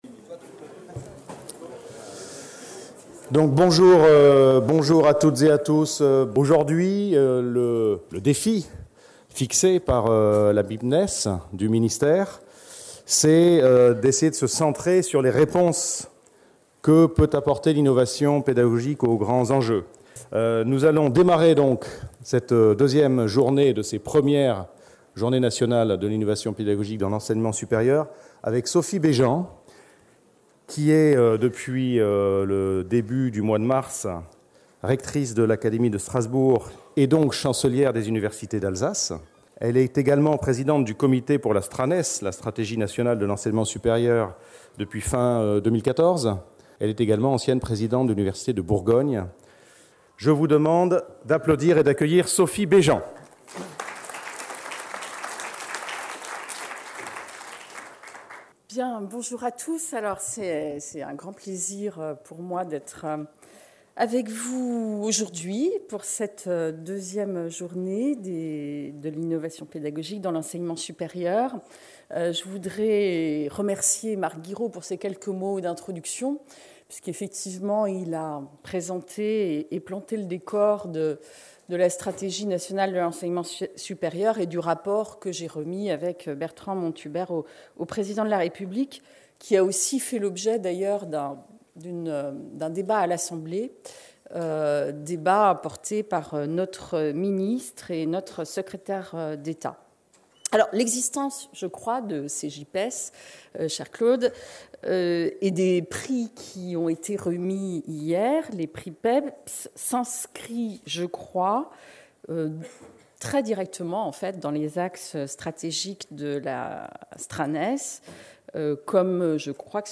JIPES 2016 // Conférence : Les grands enjeux de l'enseignement supérieur | Canal U
Vendredi 1er avril 2016 Conférence Les grands enjeux de l'enseignement supérieur Sophie BÉJEAN, rectrice de l’académie de Strasbourg, chancelière des universités d’Alsace, présidente du comité StraNES